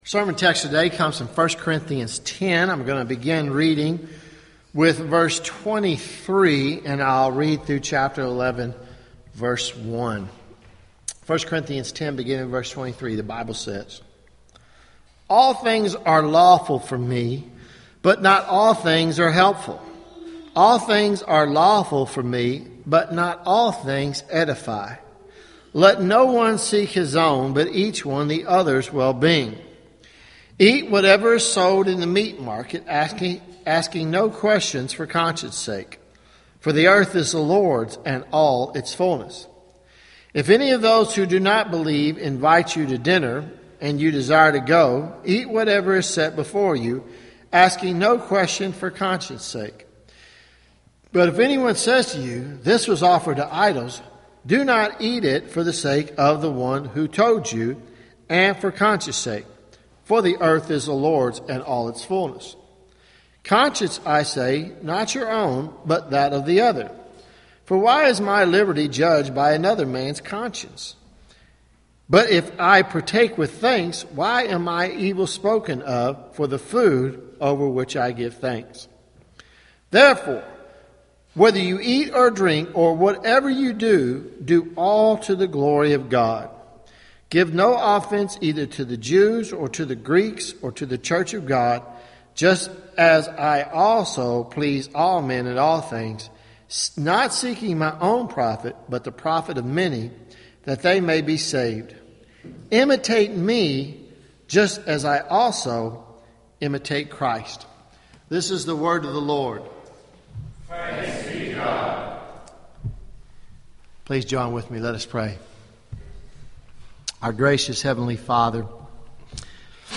Sermons Oct 26 2014 “The Glory of God” on John Calvin